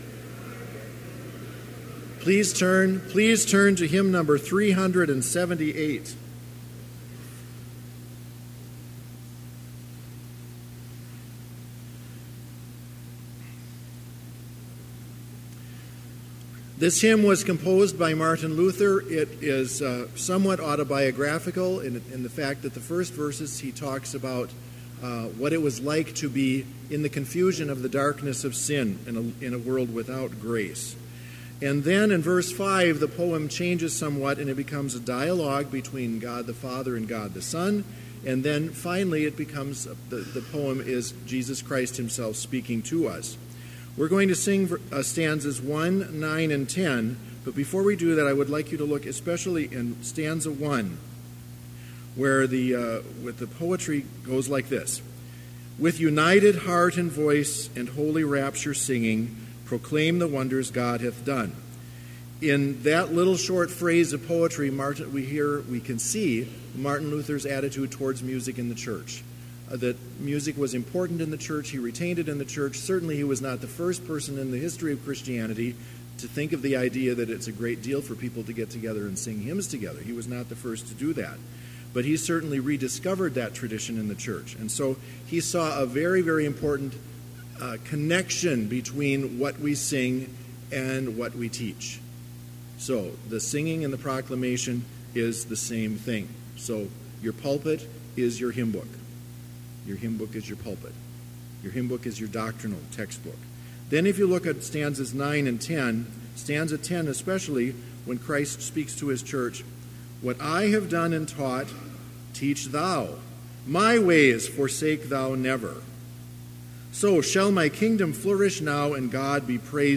Complete service audio for Chapel - October 30, 2018